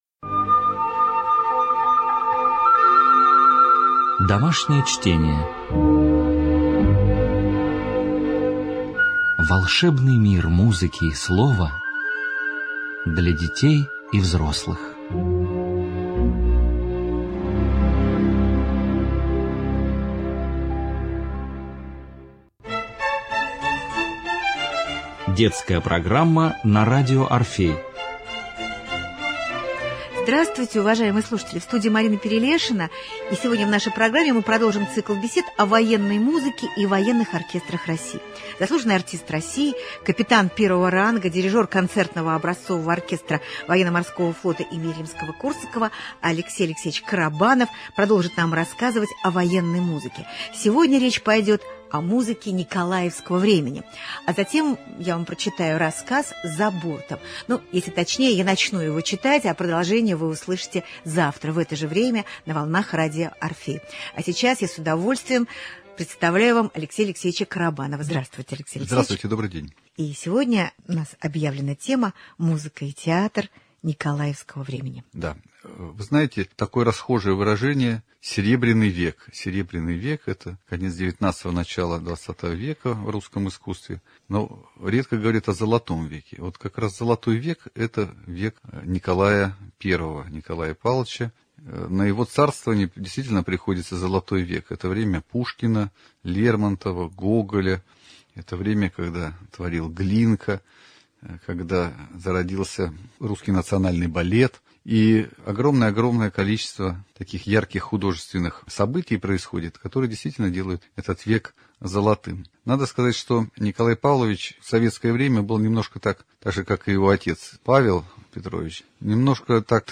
Цикл бесед о патриотической и военной музыке.